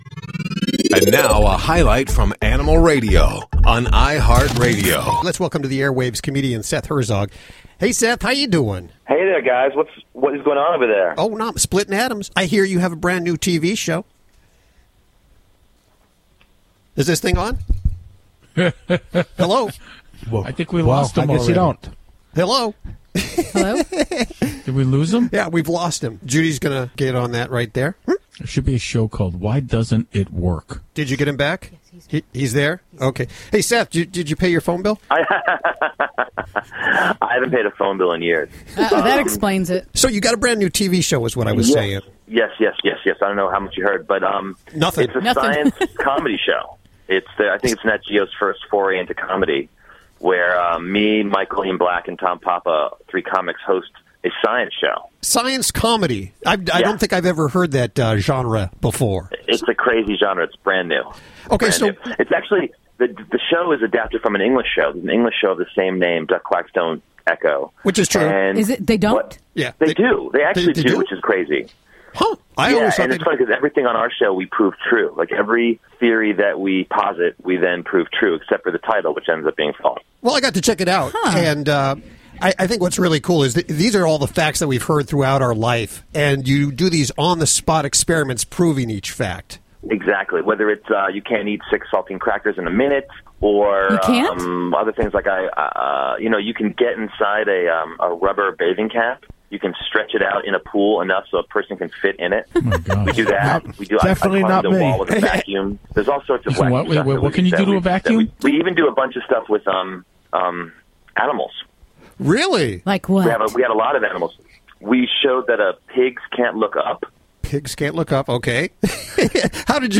Comedian SETH HERZOG is our guest and he's hosting a new television show on Nat Geo. It's the network's first stab at comedy/science.